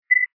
message_ticker.mp3